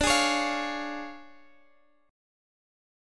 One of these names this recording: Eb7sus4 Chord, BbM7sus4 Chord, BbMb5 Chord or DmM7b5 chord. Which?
DmM7b5 chord